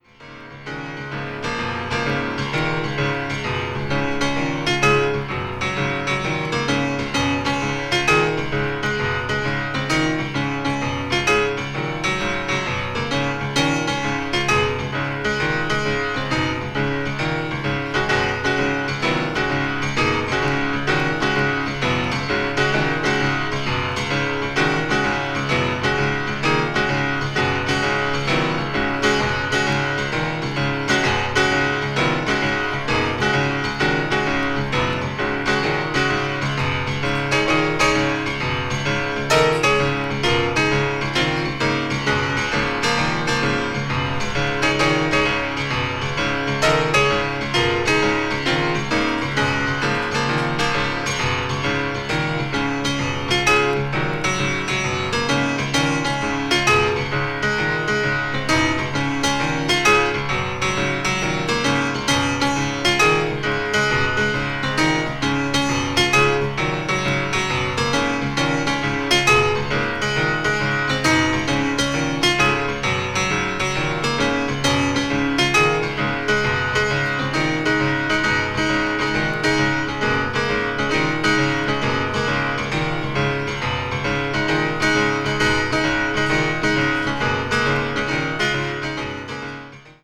media : EX/EX(some slightly noise.)
avant-jazz   contemporary jazz   free jazz   spiritual jazz